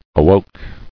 [a·woke]